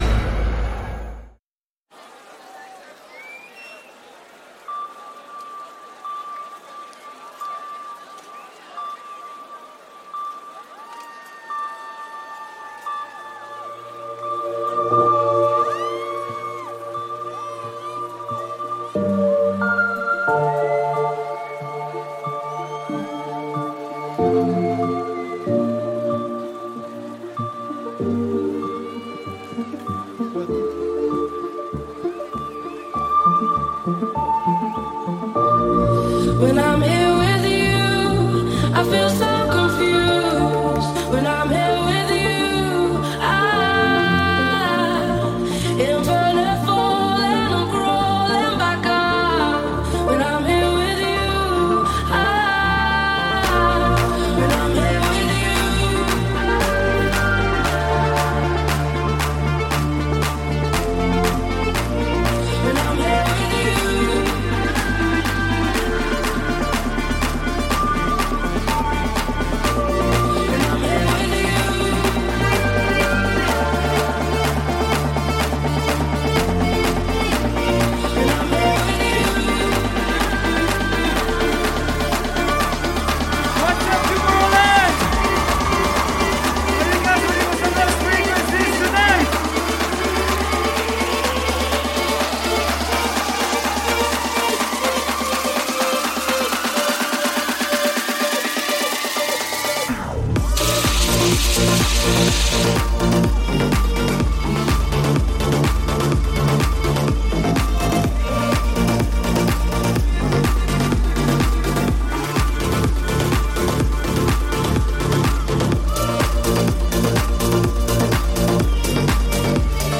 Also find other EDM Livesets,
Liveset/DJ mix